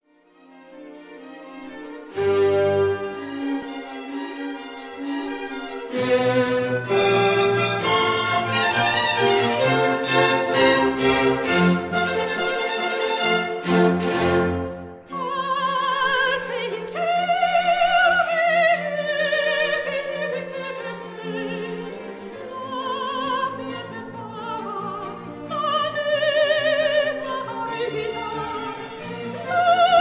concert aria
soprano
Recorded in Paris on 12 October 1955